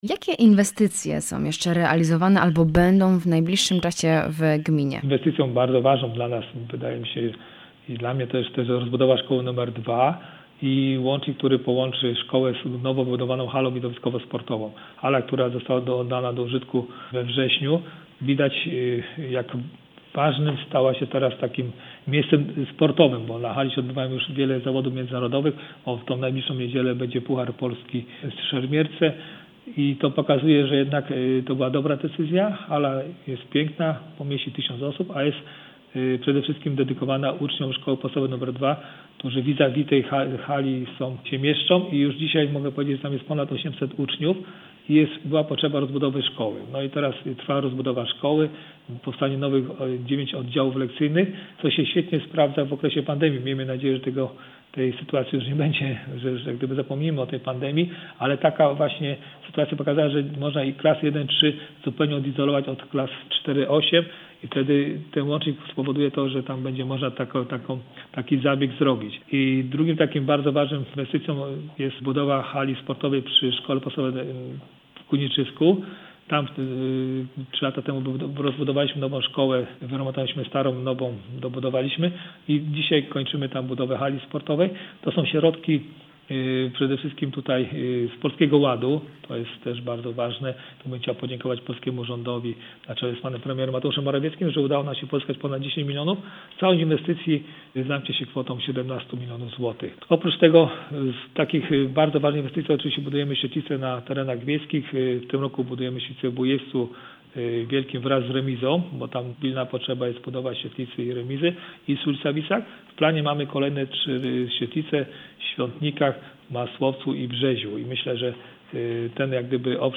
02_rozmowa-z-burmistrzem-Trzebnica.mp3